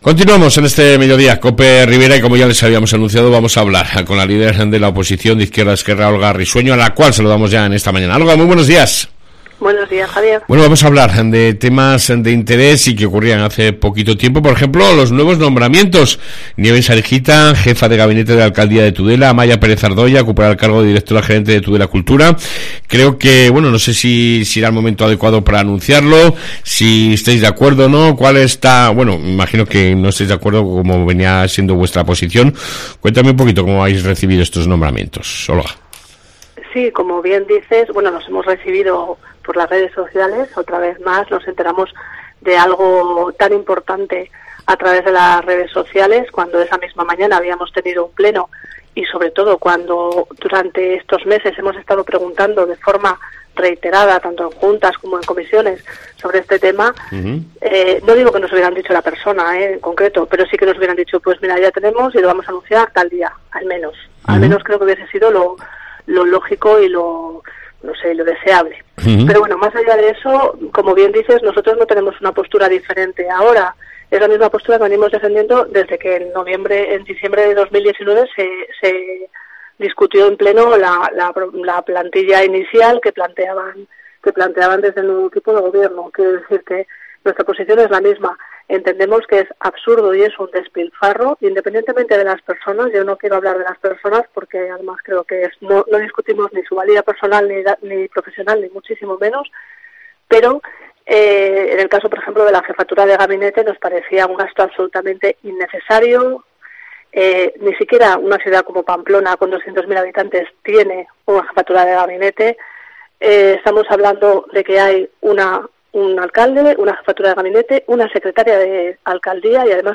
AUDIO: Entrevista con la Concejal de la oposición Olga Risueño